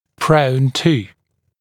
[prəun tuː][проун ту:]предрасположенный к …, имеющий тенденцию к …, склонный к ….